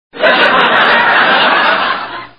FX - Laugh Track